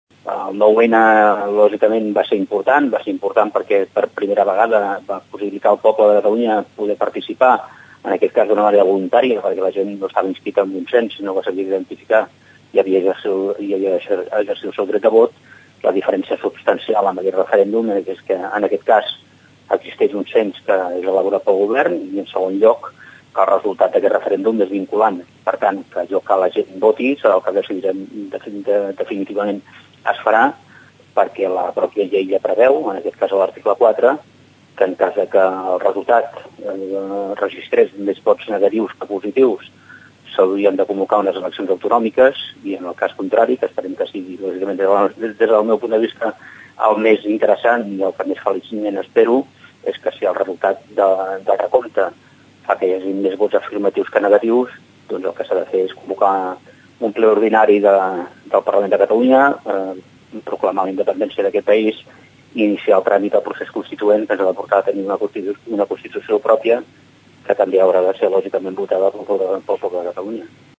L’escoltem en declaracions a Ràdio Tordera.